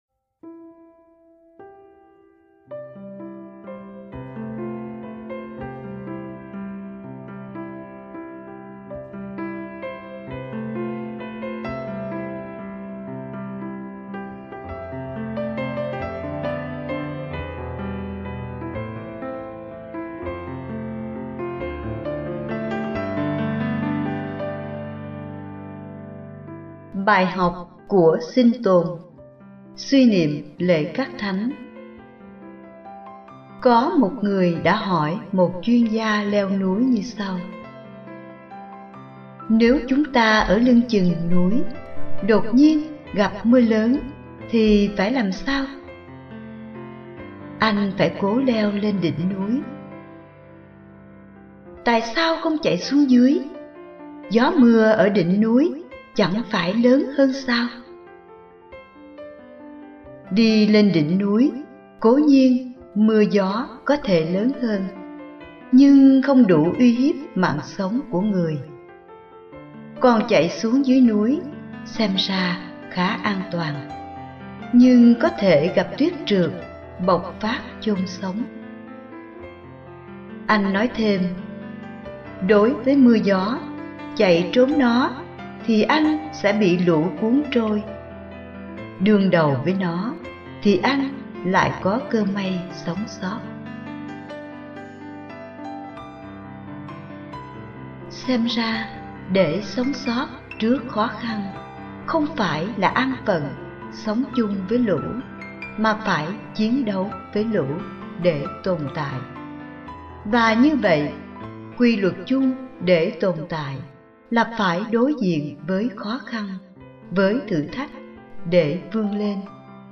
Bài giảng lễ Các Thánh 1.11.2013